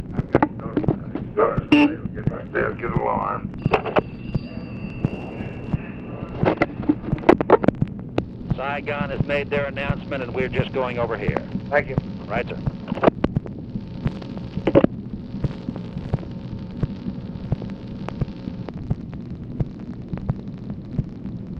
Conversation with CYRUS VANCE and OFFICE CONVERSATION, June 17, 1965
Secret White House Tapes